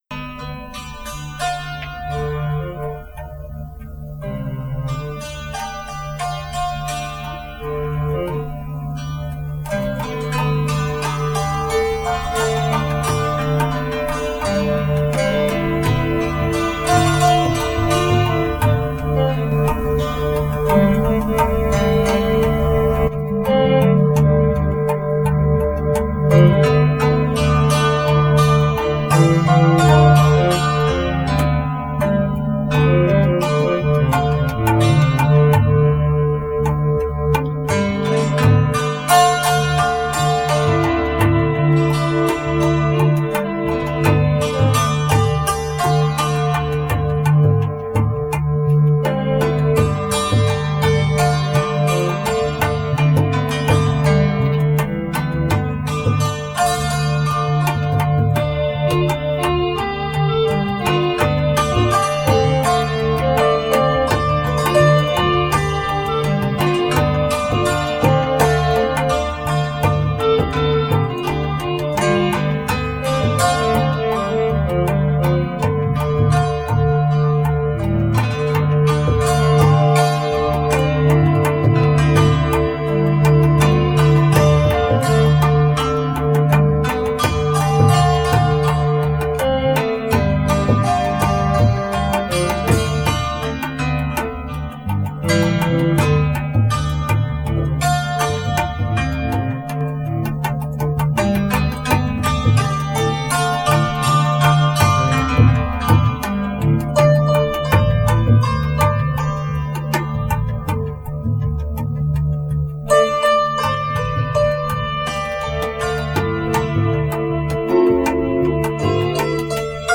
Improvisationen